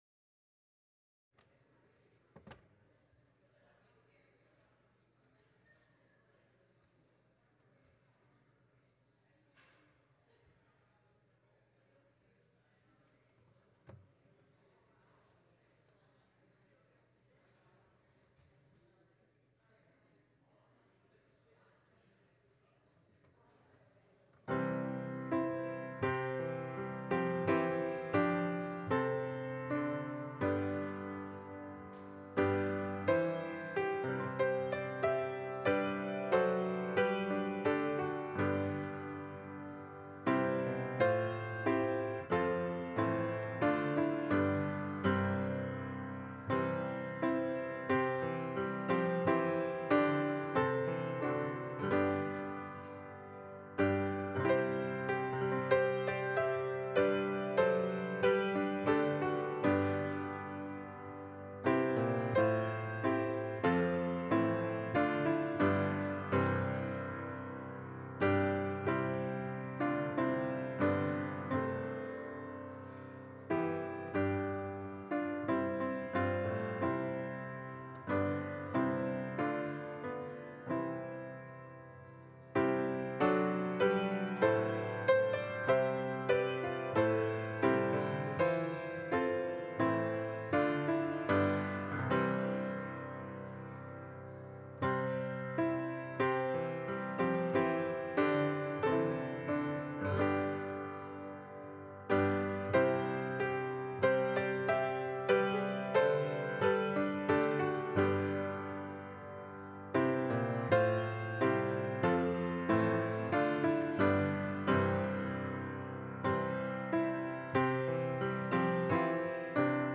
Passage: Matthew 24: 36-44 Service Type: Holy Day Service